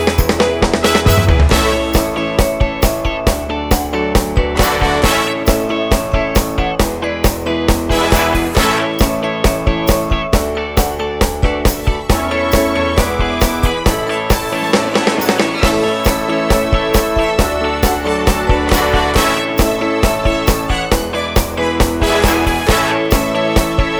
Dry Backing Vocals only Pop (1970s) 3:20 Buy £1.50